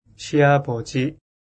発音と読み方
시아버지 [シアボジ]